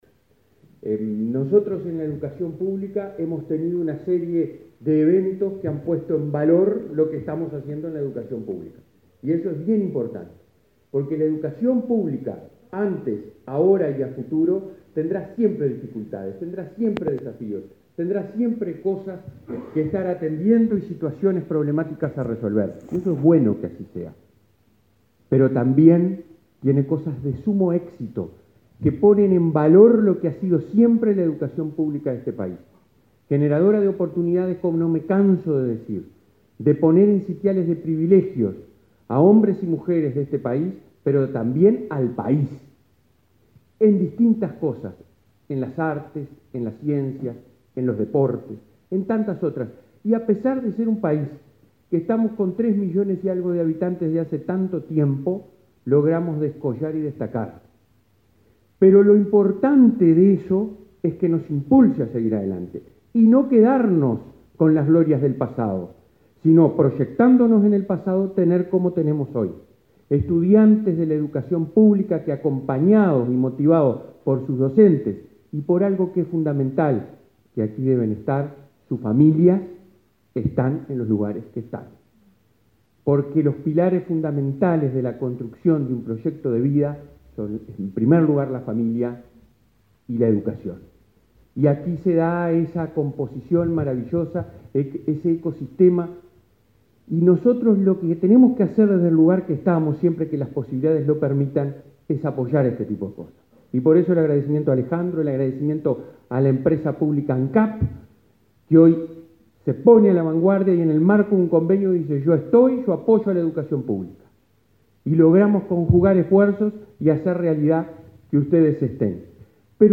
Palabras del presidente de la ANEP, Robert Silva
Estudiantes del liceo n.° 4 de Maldonado, finalistas en el concurso Desafío Espacial Latinoamericano, presentaron su proyecto antes de viajar a Estados Unidos para intervenir en la final del Concurso Internacional de Diseño de Asentamientos Espaciales, organizado por la Administración Nacional de Aeronáutica y el Espacio (NASA, por su sigla en inglés). El presidente de la Administración Nacional de Educación Pública (ANEP), Robert Silva, participó del acto.